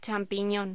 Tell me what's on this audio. Locución: Champiñón